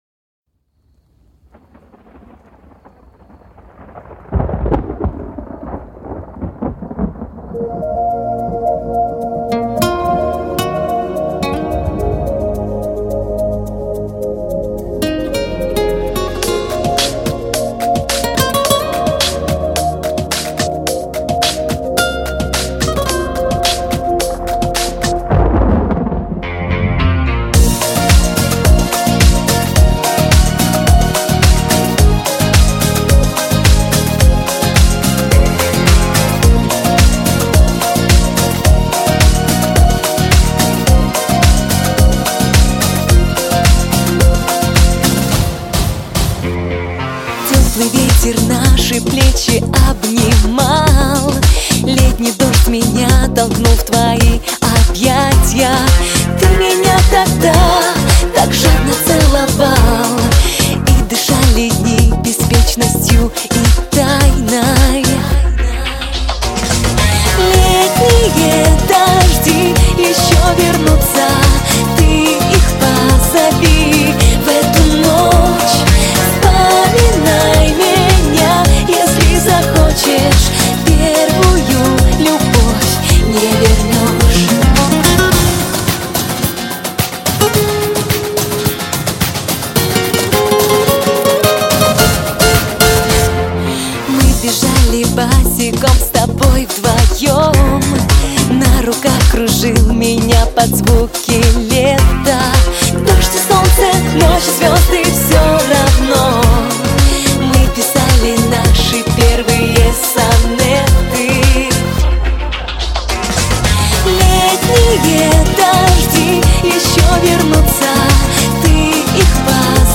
Немного шансона